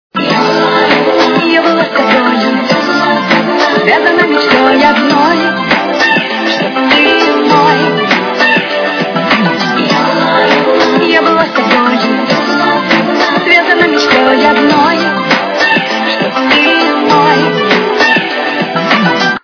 русская эстрада